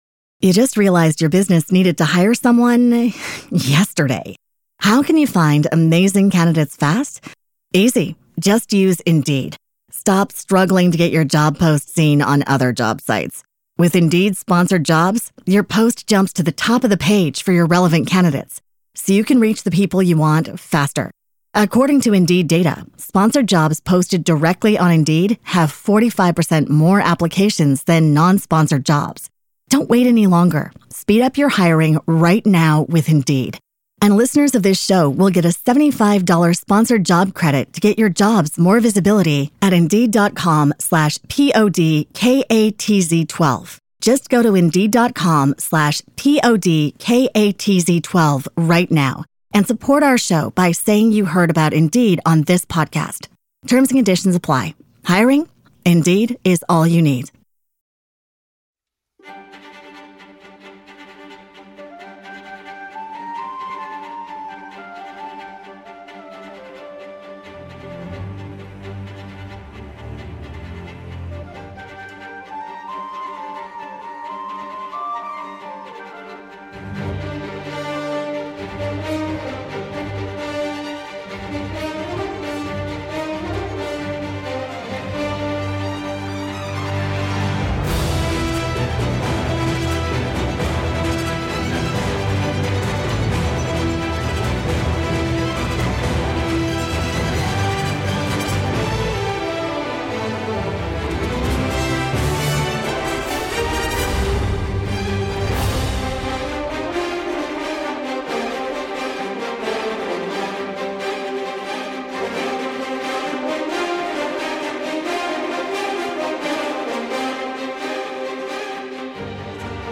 در این سری از مهمان‌ها سه سوال مشخص می‌پرسم: ۱. چه اتفاق‌هایی باعث شد در این حرفه مشغول به کار شوید؟ ۲. الان چه حسی از بودن در این حرفه دارید؟ ۳. در آینده فکر می‌کنید به چه کاری مشغول باشید و آینده صنعت را به چه شکل می‌بینید؟